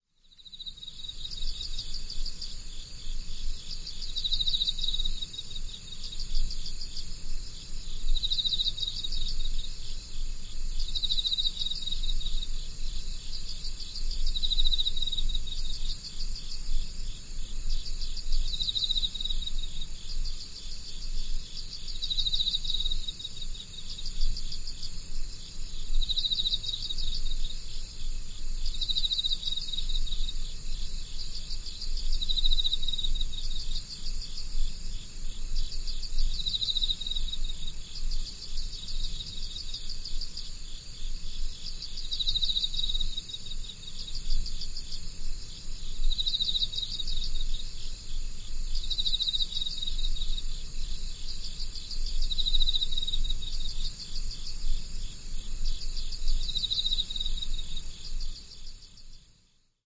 Nocturnal Insects.mp3